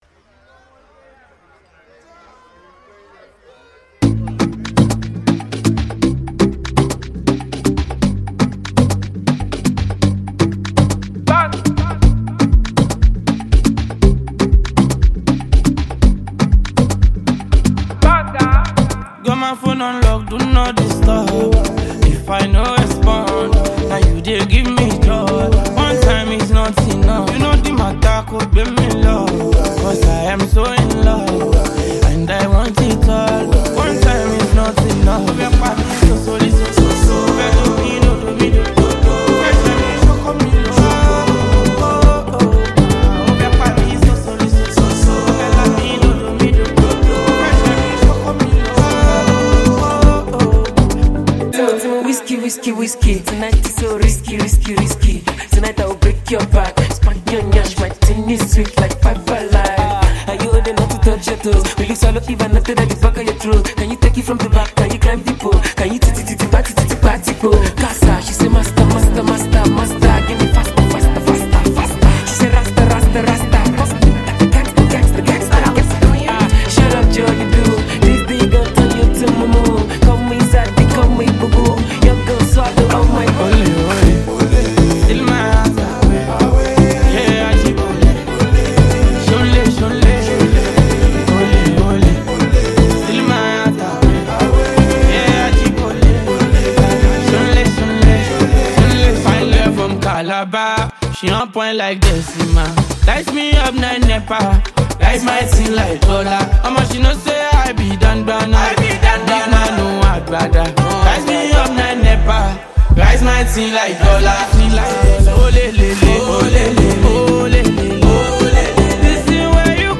On this groovy and ballad anthem